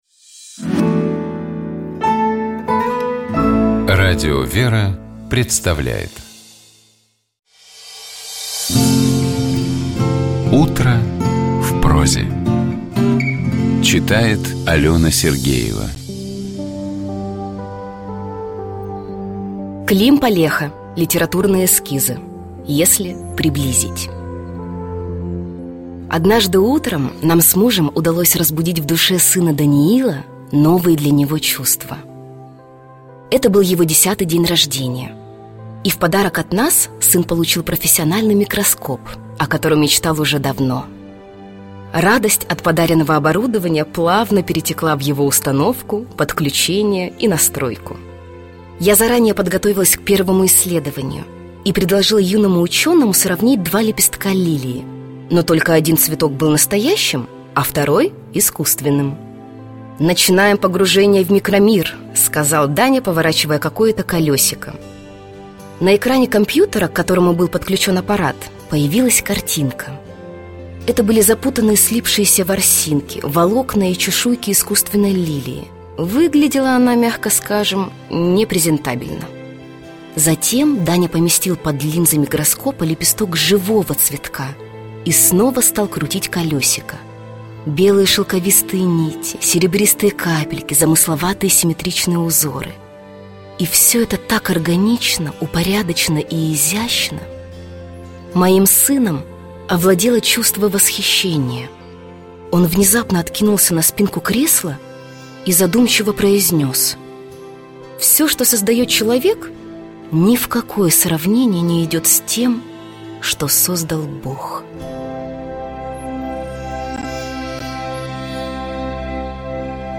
Известные актёры, режиссёры, спортсмены, писатели читают литературные миниатюры из прозы классиков и современников. Звучат произведения, связанные с утренней жизнью человека.